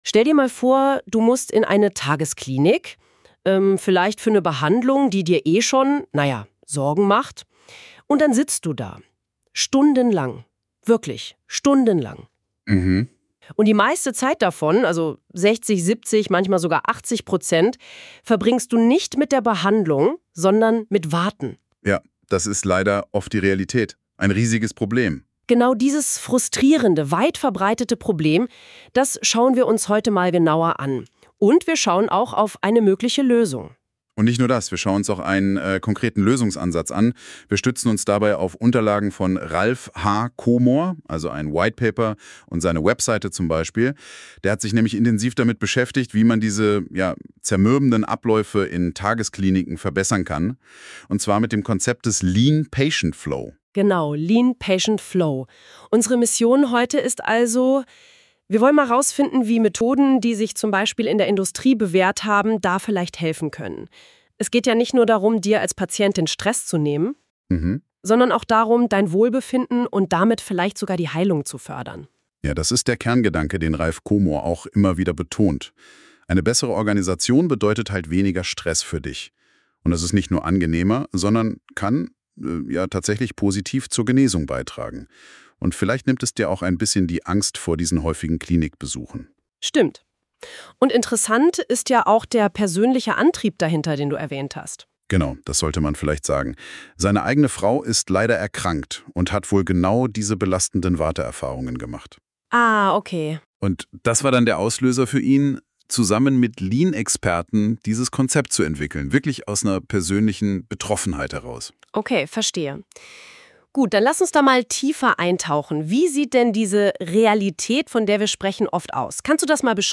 Der Podcast wurde von künstlicher Intelligenz generiert.